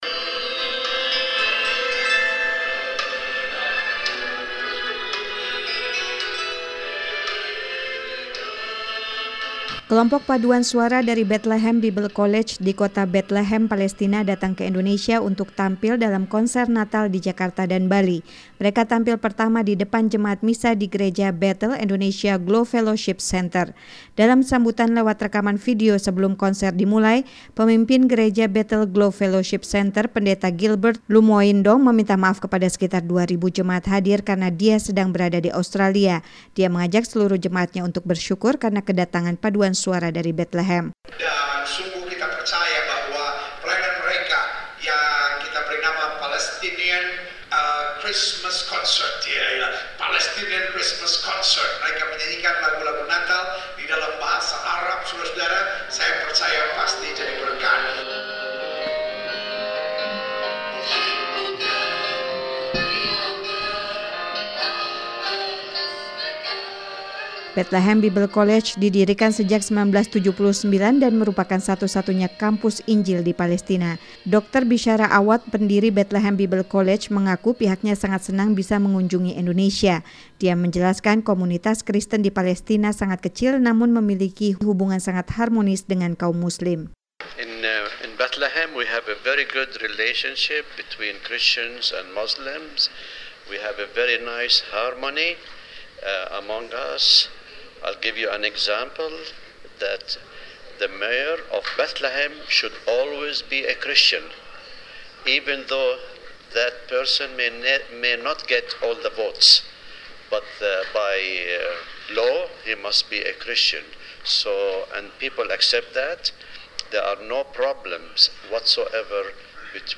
Paduan Suara Palestina Tampil dalam Konser Natal di Indonesia